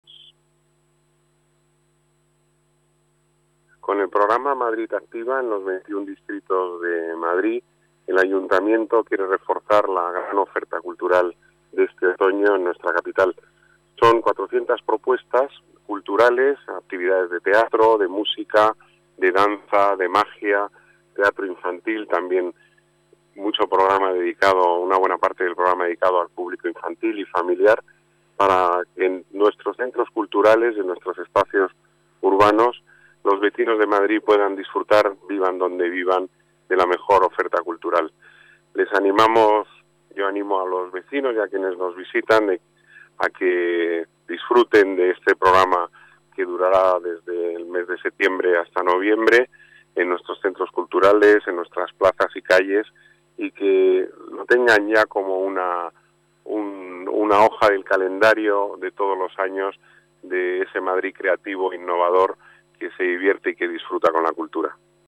Nueva ventana:Declaraciones de Pedro Corral, delegado de las Artes, Deportes y Turismo